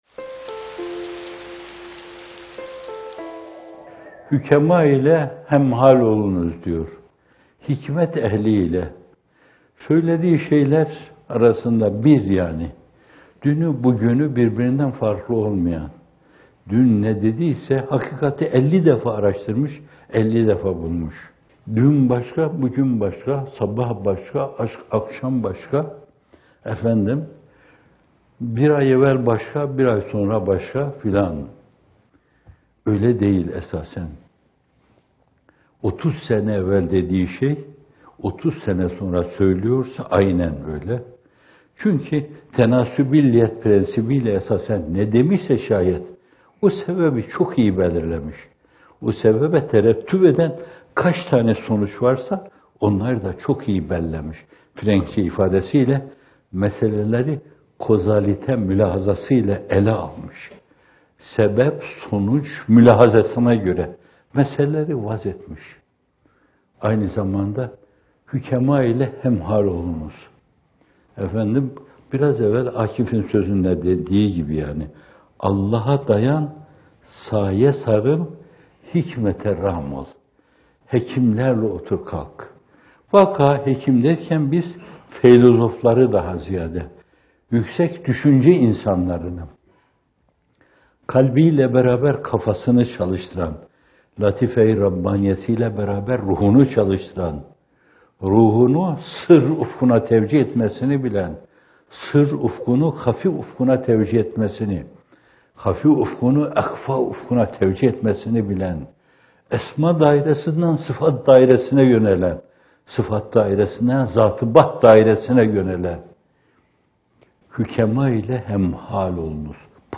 İkindi Yağmurları – Kainat Kitap, İnsan Fihrist - Fethullah Gülen Hocaefendi'nin Sohbetleri